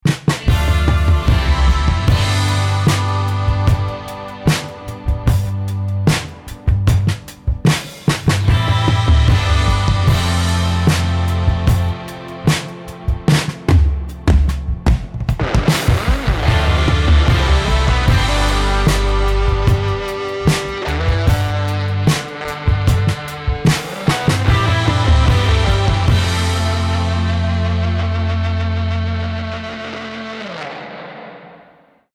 どんなトラックやミックスも、よりハードにヒットするウルトラデッド・ヴィンテージ・ドラムが魂を吹き込む